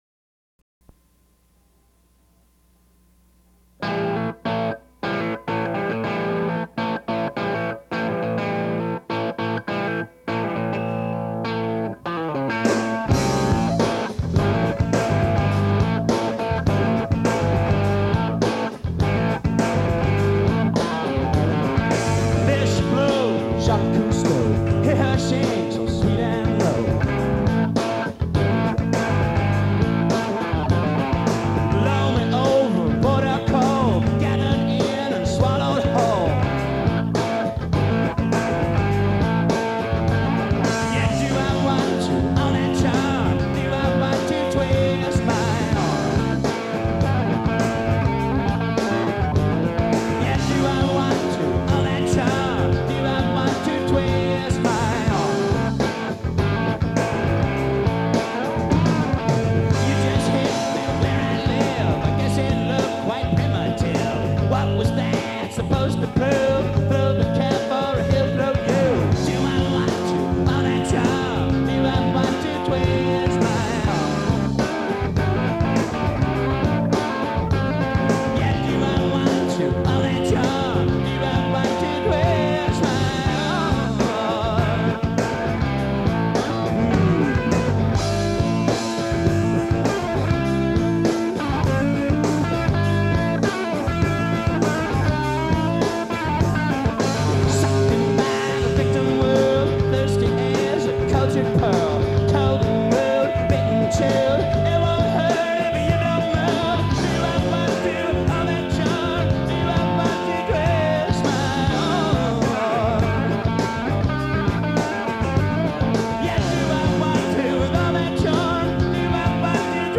Live In 1992